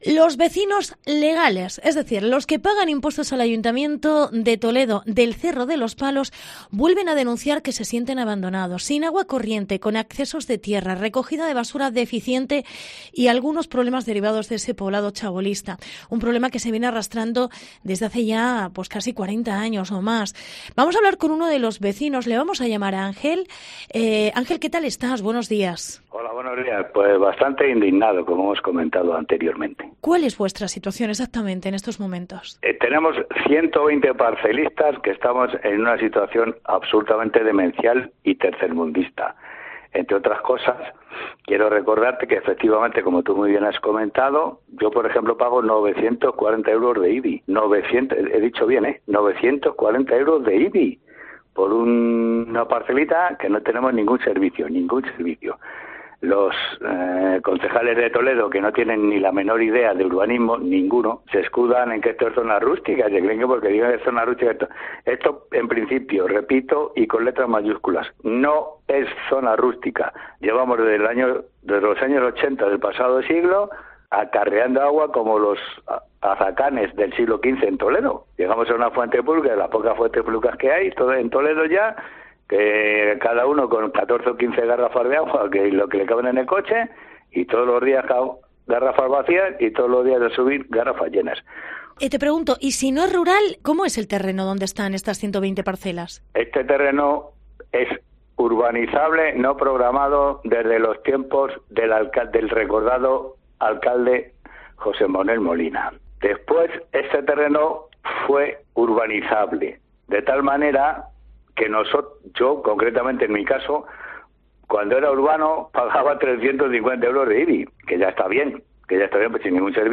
Entrevista parcelista Cerro de los Palos